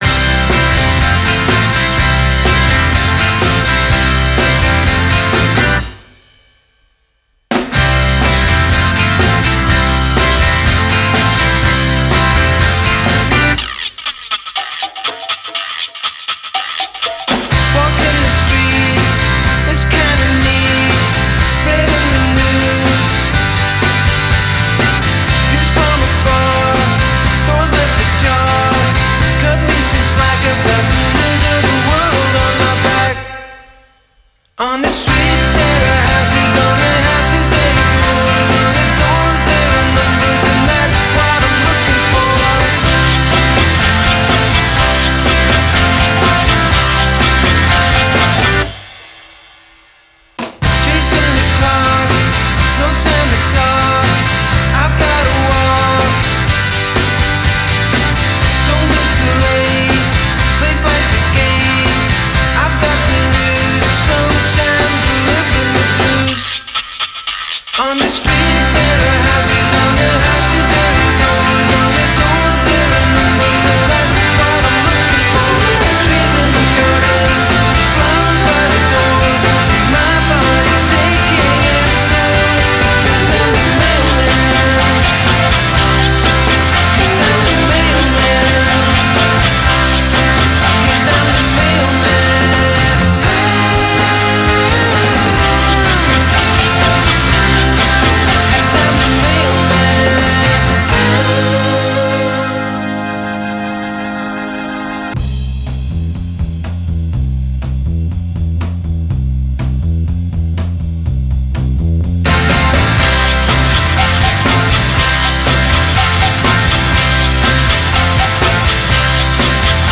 maxes out the jangle on killer catchy tunes